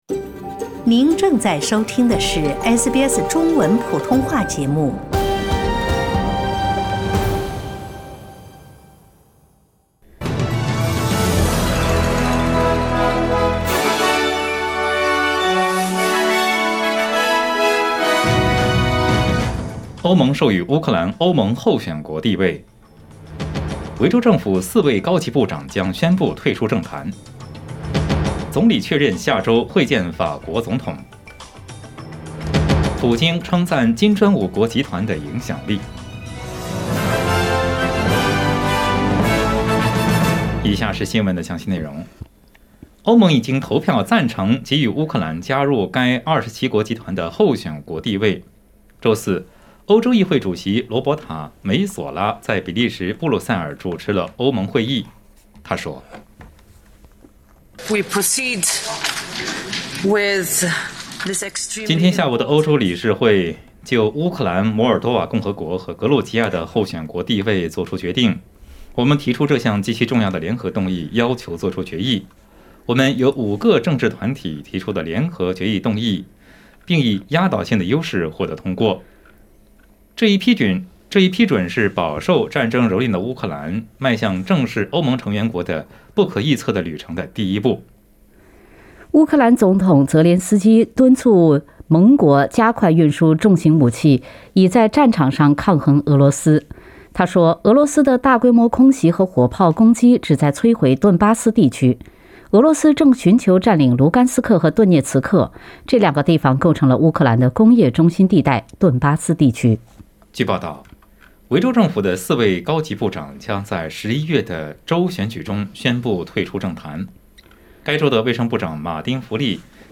SBS早新闻（6月24日）
请点击收听SBS普通话为您带来的最新新闻内容。